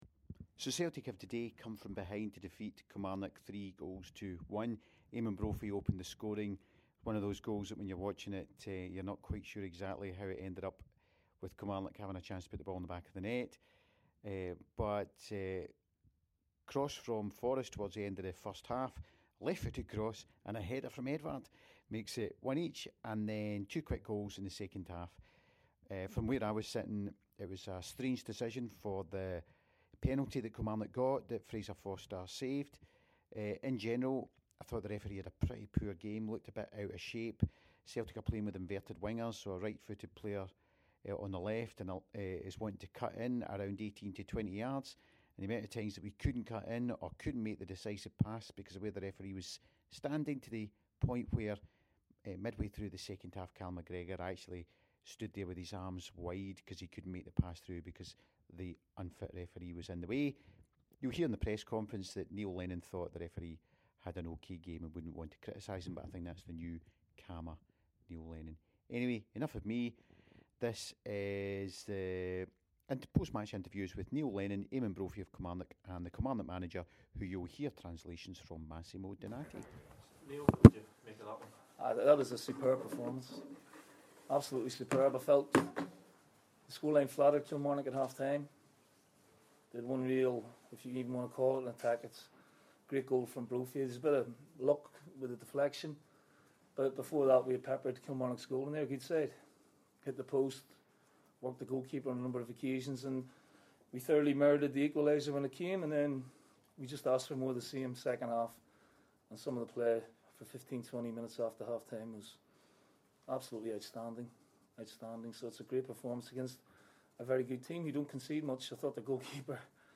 After the game we got the comments from the Celtic and Kilmarnock managers (with Massimo Donatti doing some translating).